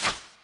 MenuOff.wav